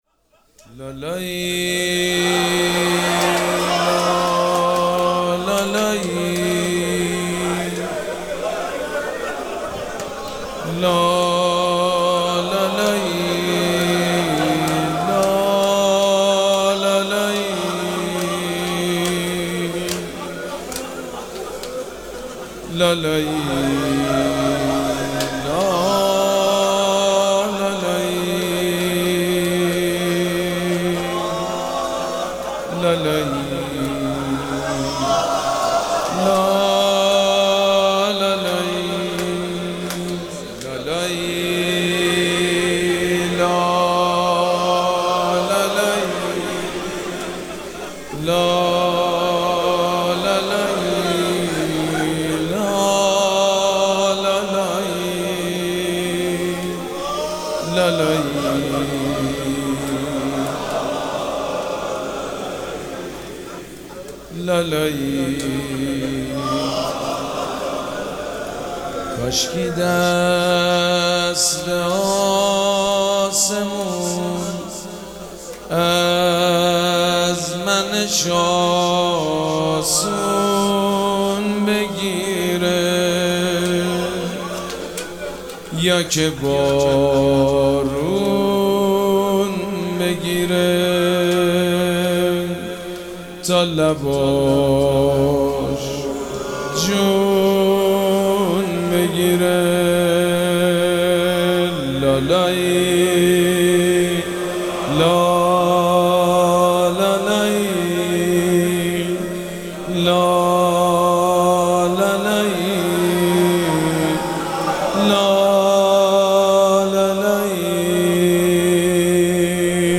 مراسم عزاداری شب هفتم محرم الحرام ۱۴۴۷
روضه